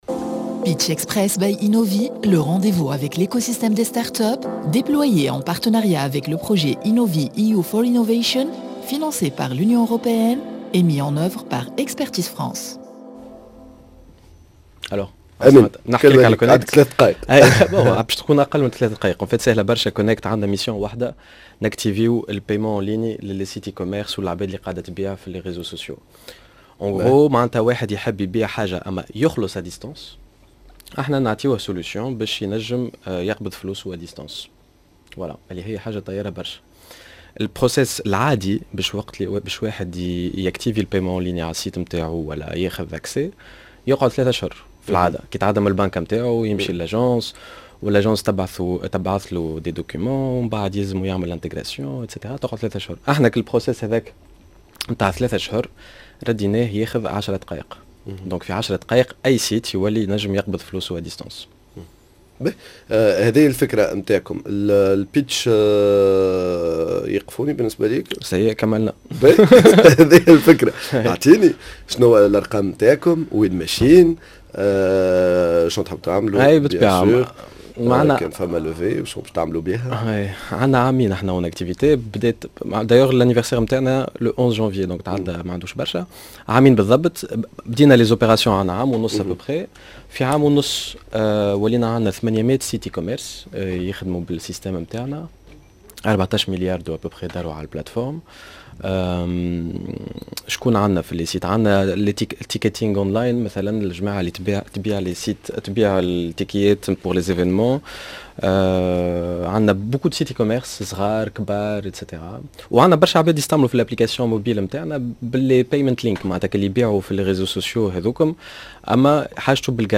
pitch